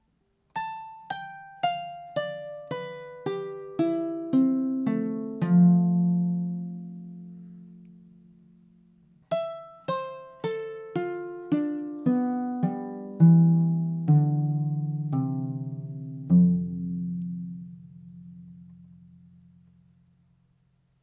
最低音Fのハ長調に調整済み。
瓢箪やや小さめ、ネック短めでコンパクトな楽器です。中音域の柔らかい響きが良いです。
ギニア製コラ 音 kora from Guinea